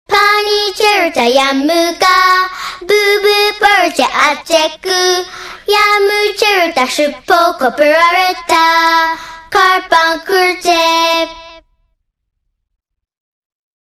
分类: 短信铃声